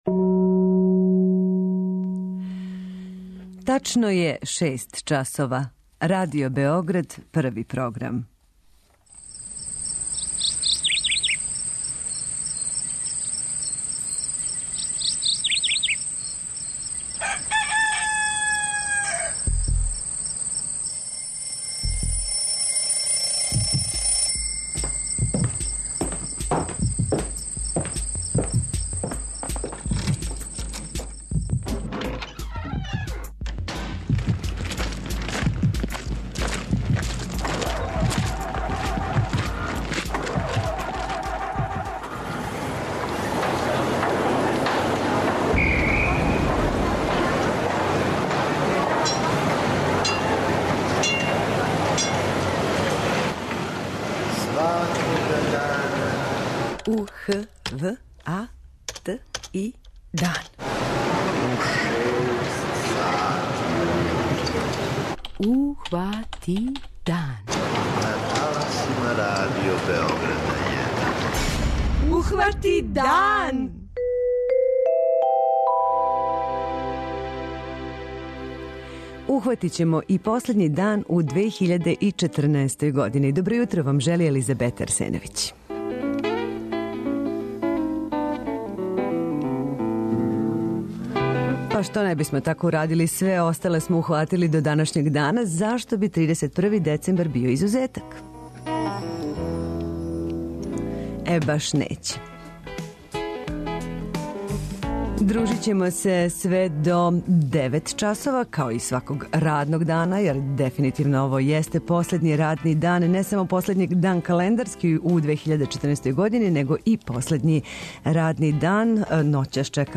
Прогноза времена, стање на путевима, извештаји са лица места о томе како се поједини центри у Србији припремају за јединствену ноћ и целој години, уз представљање два пројекта - "Ортогонално", групе младих ентузијаста који желе да знања из разних научних области на потпуно другачији начин приближе нашим школарцима, и "Београдски читач", који би требало да резултира подизањем споменика читању, као важној категорији духа, у центру Београда - то су најважнији сегменти Јутарњег програма "Ухвати дан" у последњем издању за 2014.